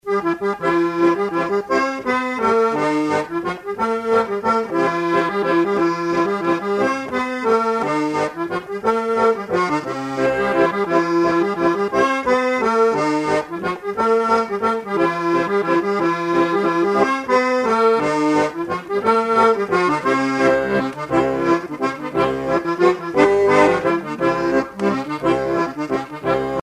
Suite de mazurkas
danse : mazurka